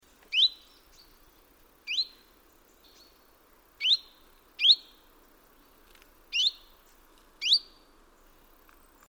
Chiffchaff calls
All from Northwestern Estonia, 10-11 September 2005.
Different individual.